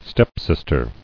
[step·sis·ter]